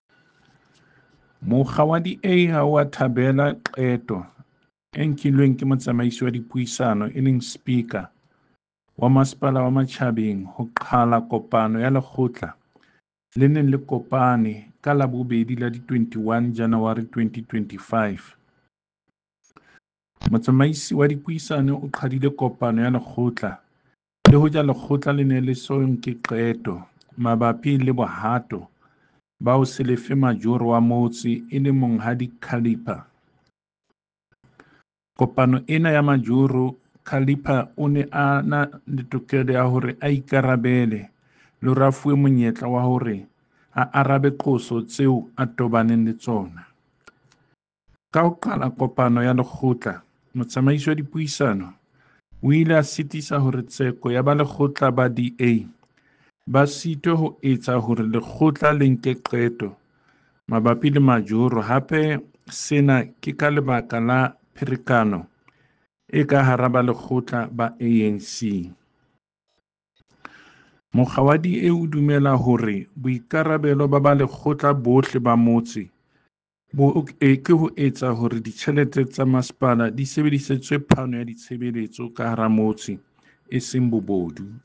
Sesotho soundbite by David Masoeu MPL